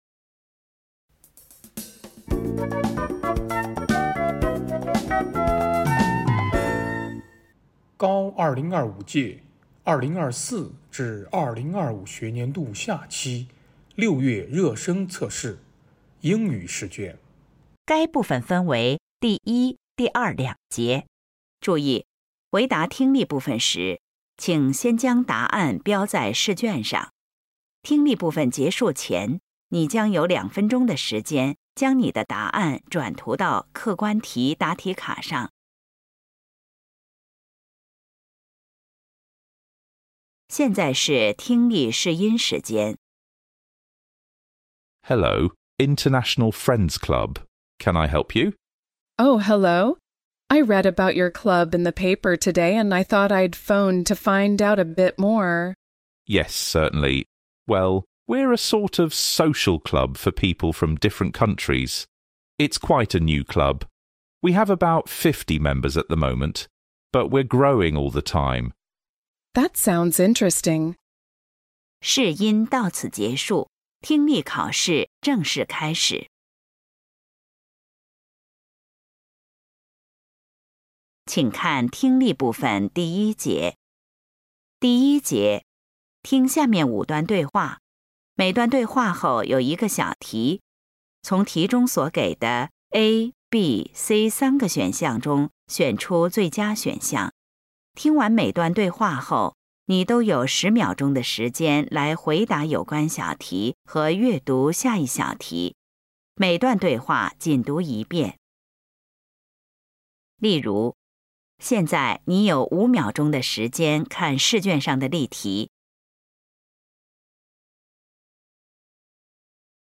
成都七中2025届高考热身考试英语听力.mp3